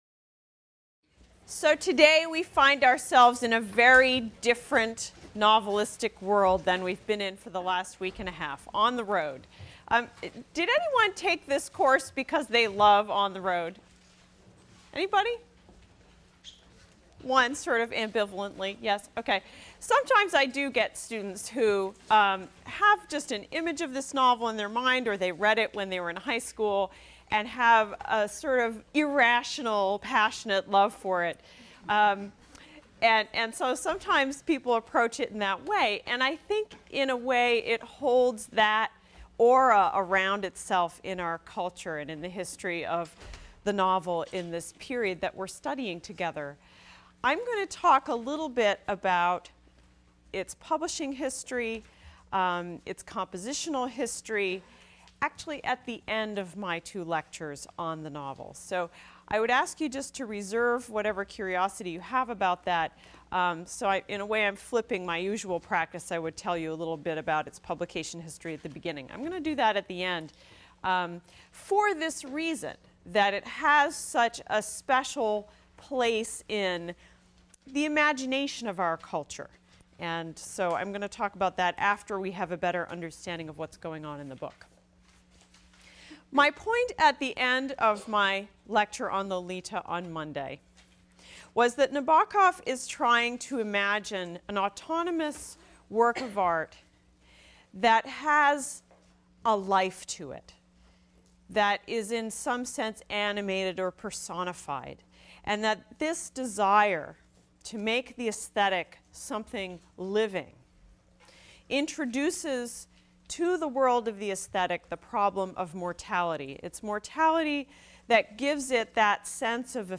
ENGL 291 - Lecture 8 - Jack Kerouac, On the Road | Open Yale Courses